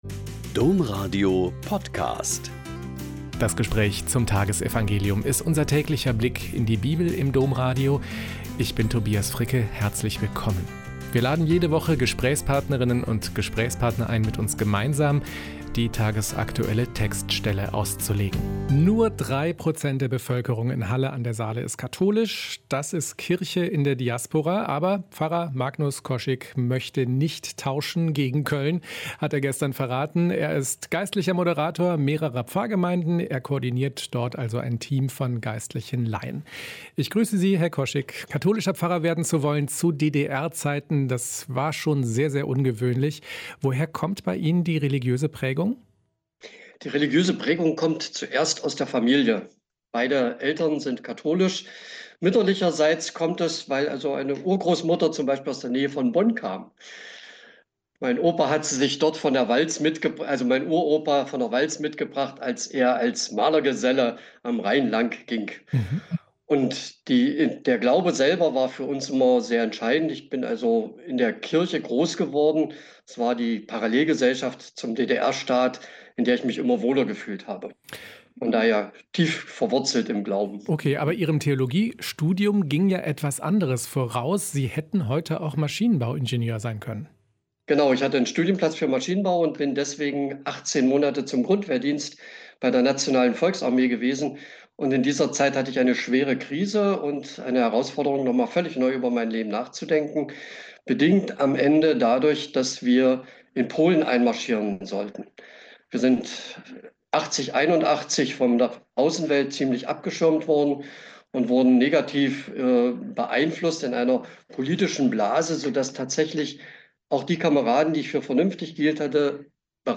Mk 7,1-13 - Gespräch